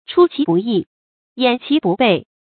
chū qí bù yì，yǎn qí bù bèi
出其不意，掩其不备发音